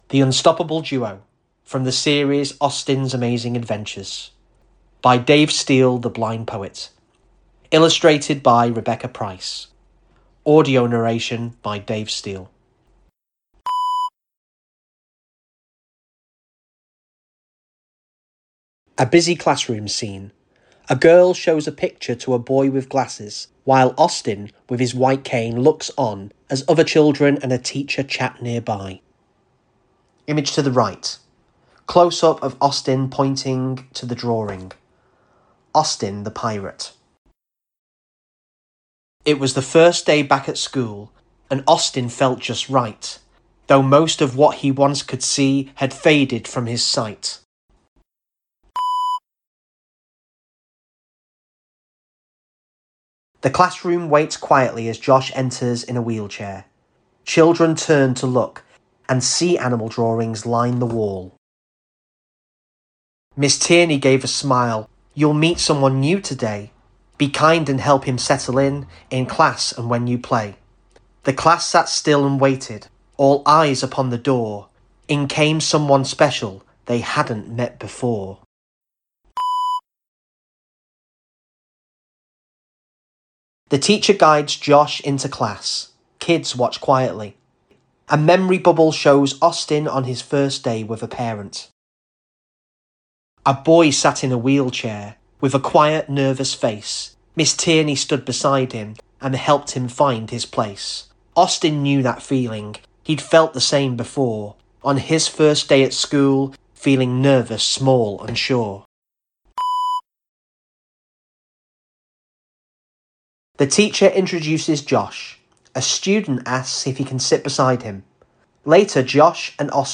You will hear him narrate the text and a description of the illustration. Then a 'beep' will signal you to turn the page.